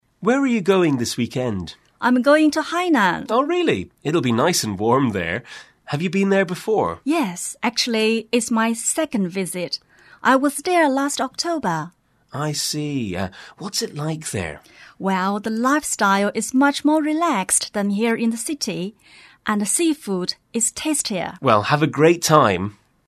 英语初学者口语对话第02集：这个周末你打算去哪儿？